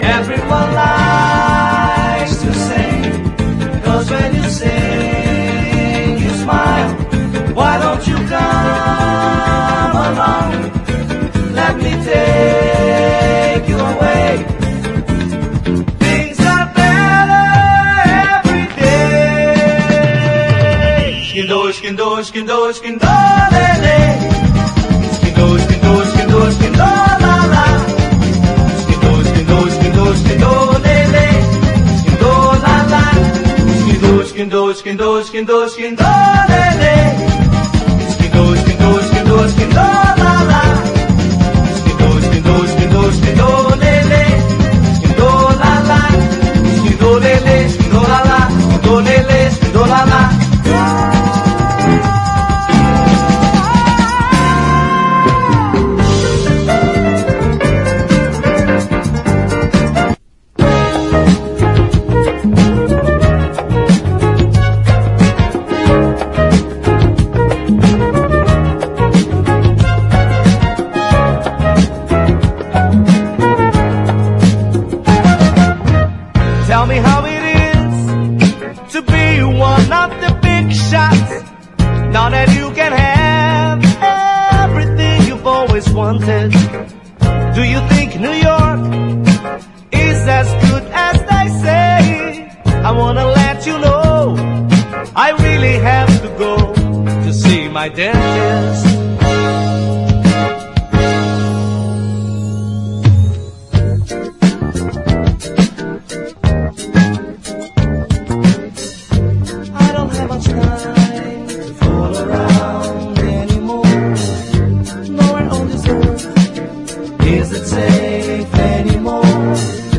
SOUL / RARE GROOVE/FUNK / SOUTHERN SOUL
熱いグルーヴと相性抜群の塩辛いヴォーカル、J.B.系ファンク好きなら強烈なホーン・リフ一発で撃沈確実！